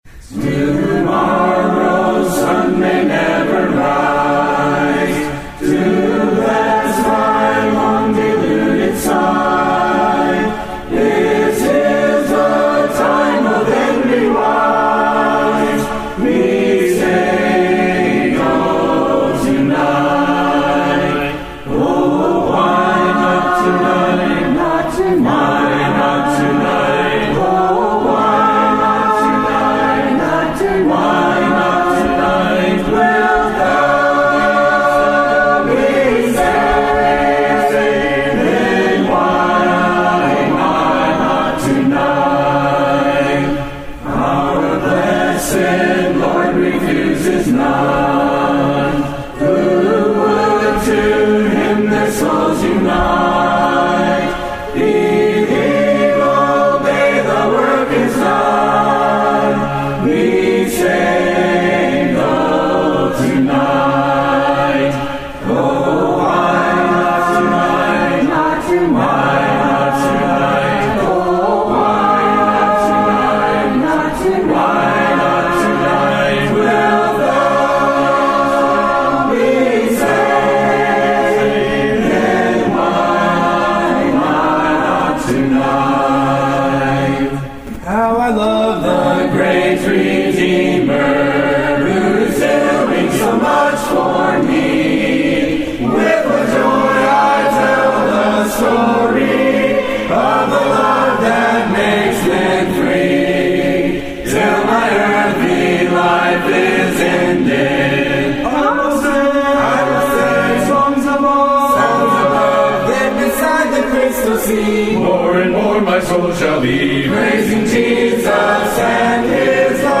Christians Singing Hymns
Last Sunday evening, a group of Christians came together to sing hymns of praise and I wanted to share some of the beautiful hymns that were sung.